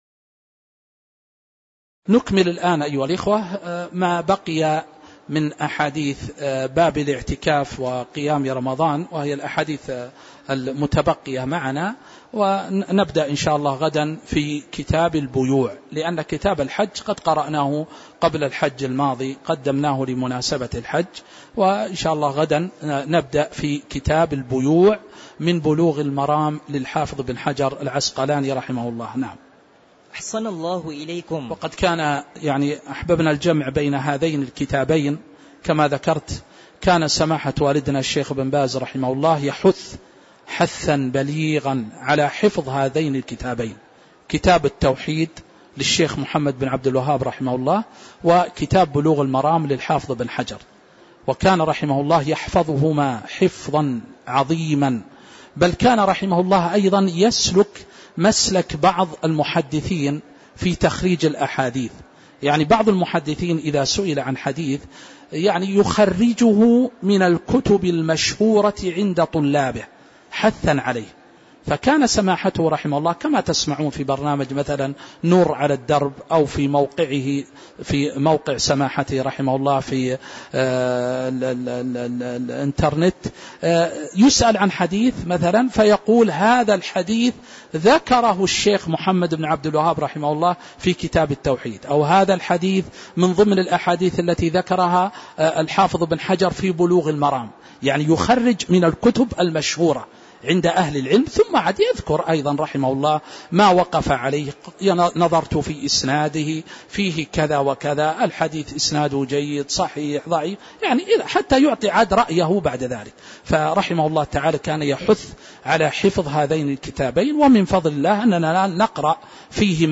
تاريخ النشر ١٨ ربيع الأول ١٤٤٦ هـ المكان: المسجد النبوي الشيخ